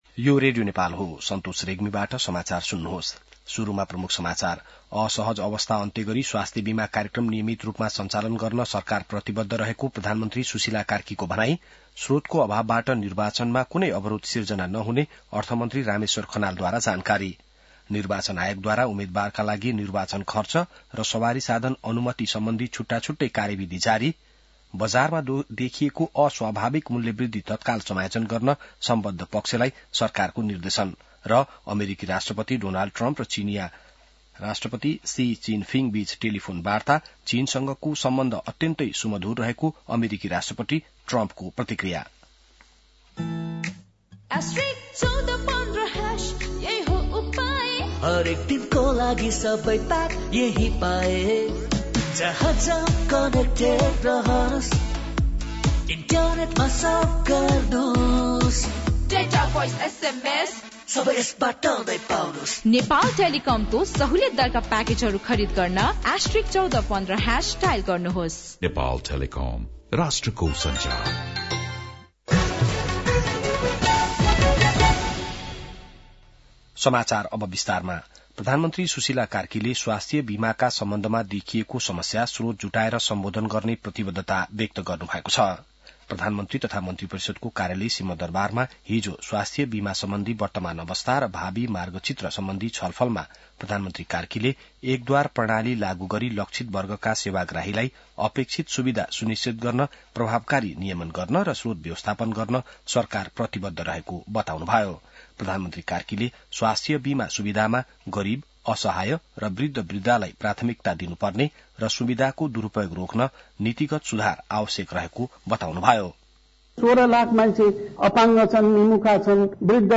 An online outlet of Nepal's national radio broadcaster
बिहान ७ बजेको नेपाली समाचार : २२ माघ , २०८२